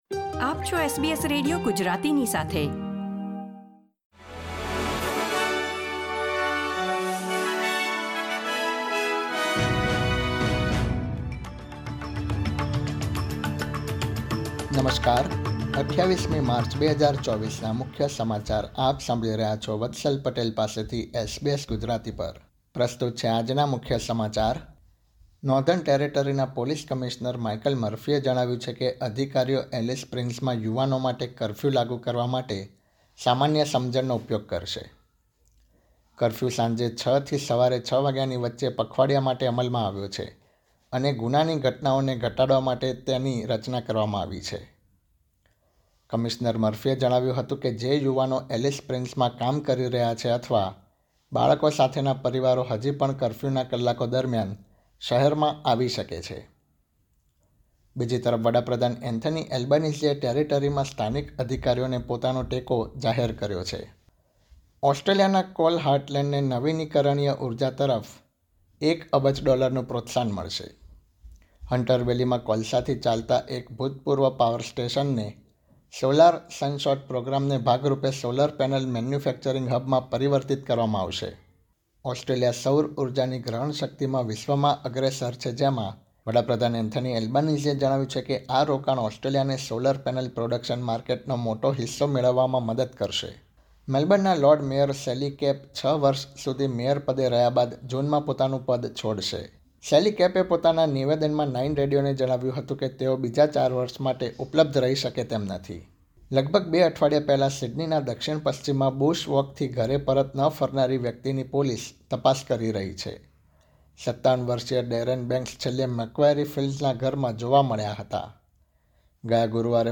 SBS Gujarati News Bulletin 28 March 2024